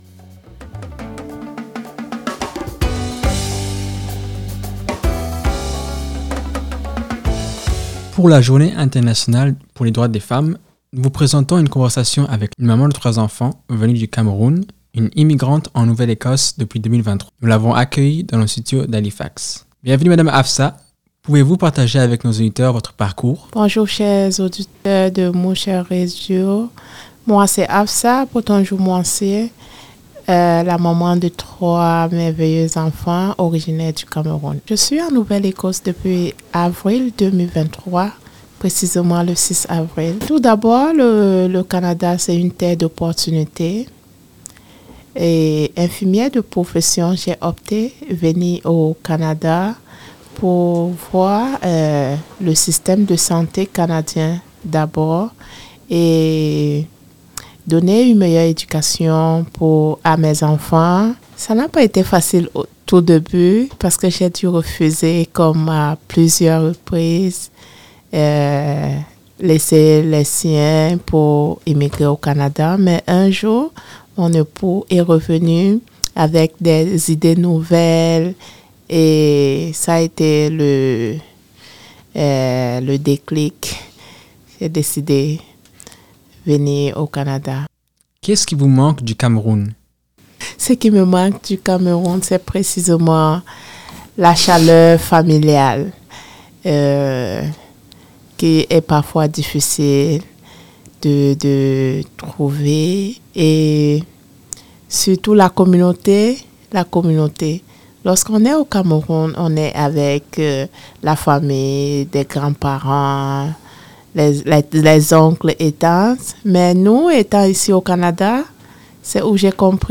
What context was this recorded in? Nous l’avons accueillie dans nos studios d’Halifax. Vous allez aussi entendre les 3 enfants.